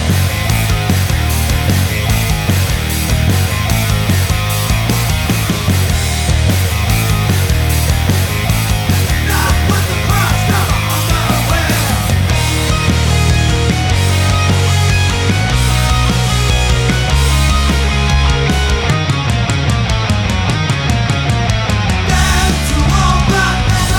no Backing Vocals Rock 2:29 Buy £1.50